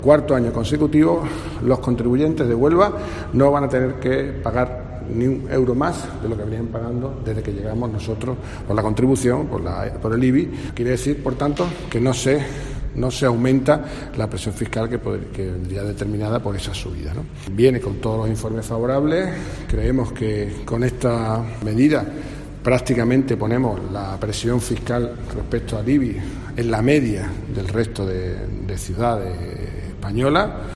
Manuel Gómez, portavoz del equipo de gobierno de la capital